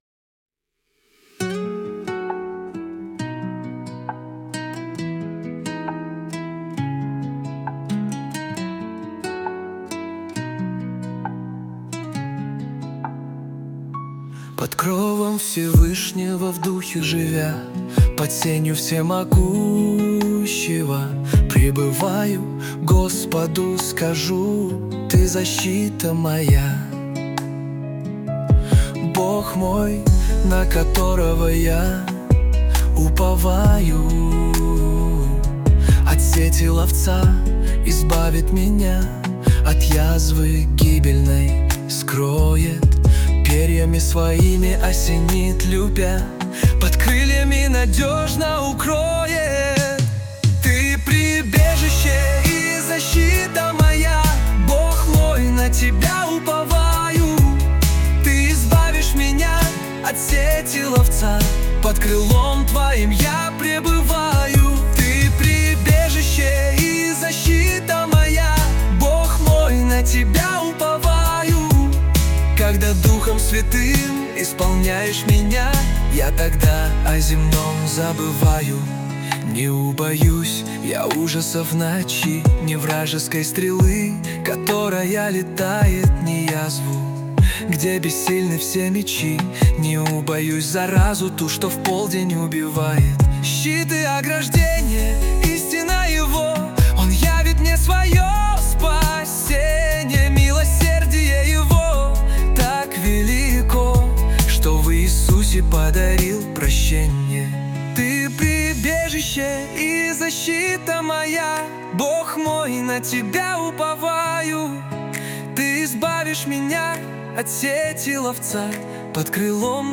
300 просмотров 1152 прослушивания 128 скачиваний BPM: 67